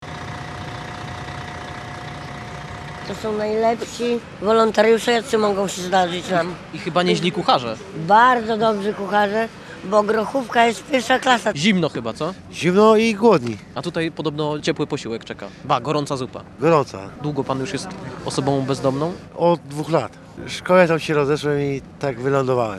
Na przystanek autobusu SOS w Gdańsku wybrał się nasz reporter.